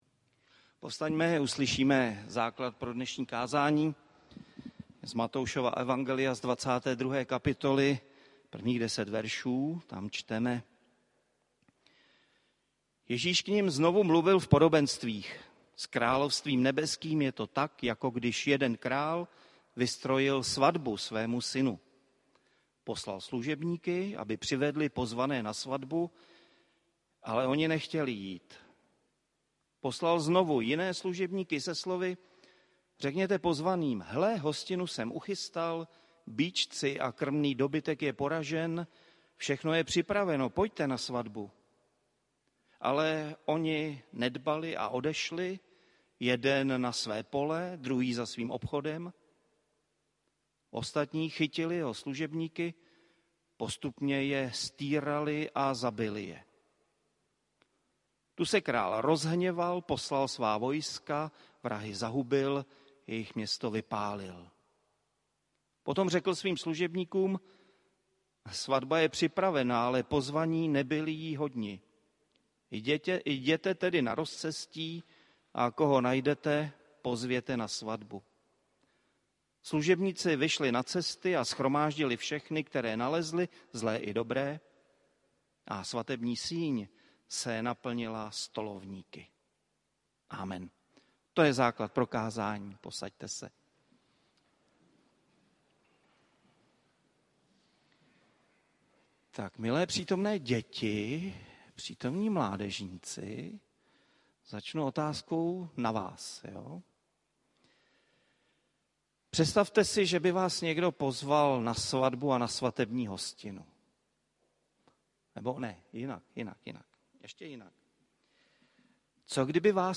Čtvrtá neděle po Velikonocích – Cantate –